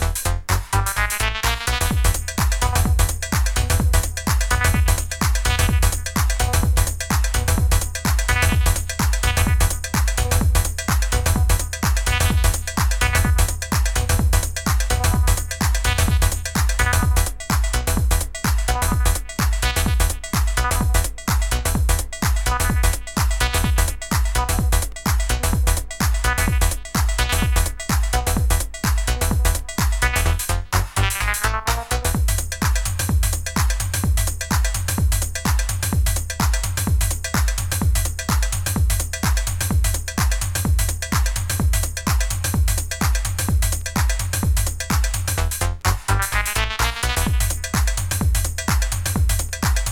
Nu-Discoタイプのビートに、切ないシンセフレーズがGood！
こちらはオーガニックなDeep House的サウンド。
独特過ぎるトランシーなHouseサウンド4トラック収録。